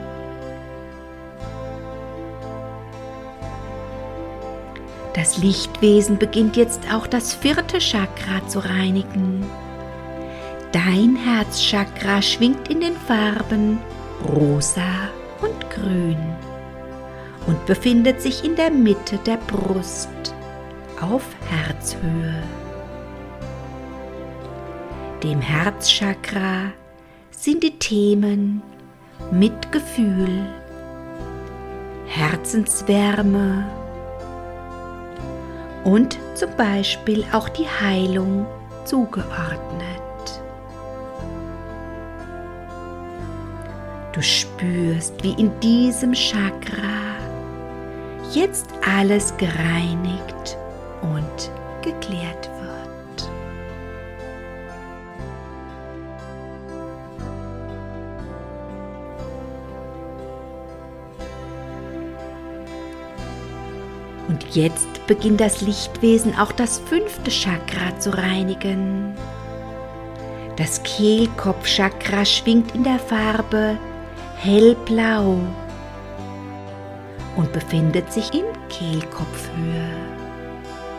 mit Hintergrundmusik (gemafrei) - eine geführte Reinigungs-Meditation mit Hintergrundmusik